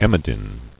[em·o·din]